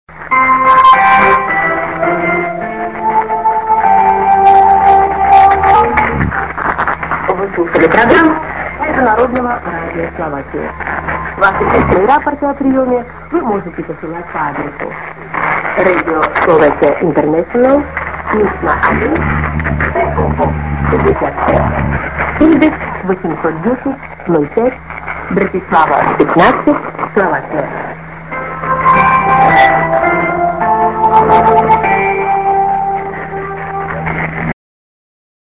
End IS->ID:"this is ･…Radio Slovakia･…"(women)->IS